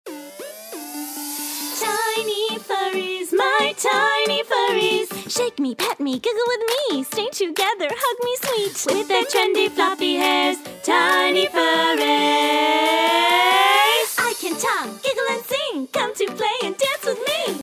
Commercial SINGING